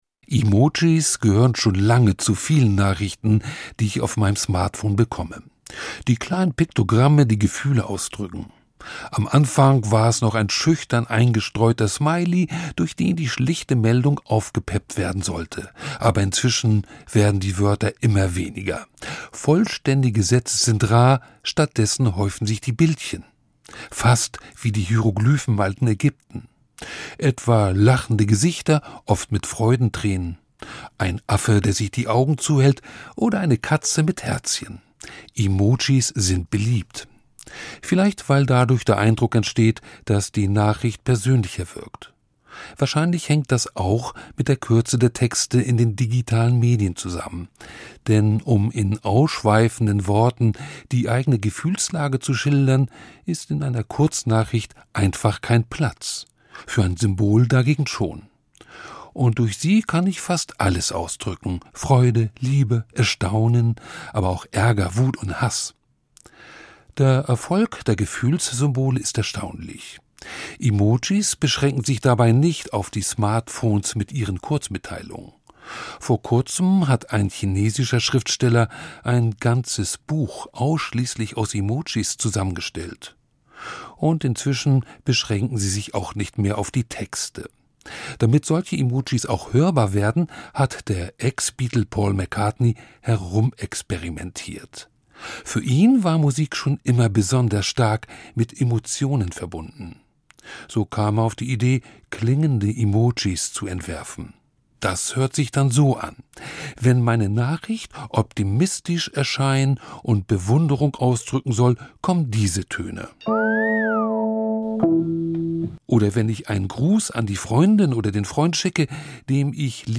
Kurz-Feature der evangelischen und katholischen Kirche zu Glaubensthemen.